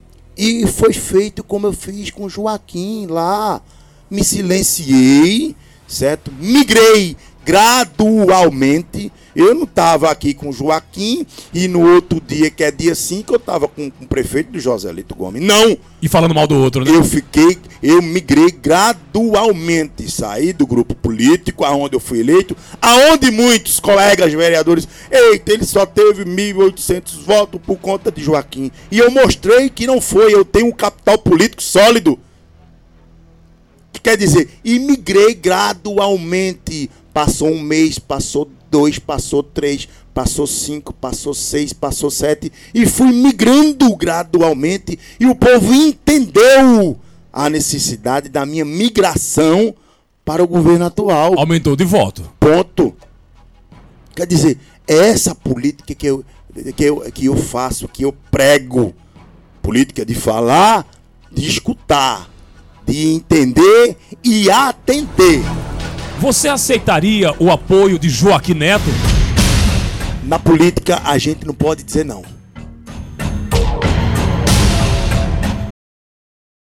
Em entrevista na Clima FM, Léo do Ar evita rompimentos e deixa portas abertas para alianças políticas em 2026
Sobre o atual prefeito Joselito Gomes, Léo do Ar adotou um tom conciliador.